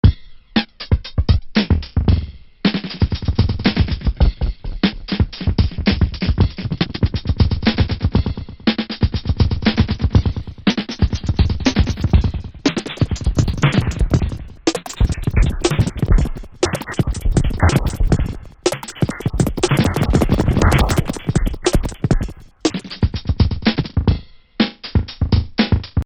Demonstrates how Warbler can also act as a delay effect.
delay.mp3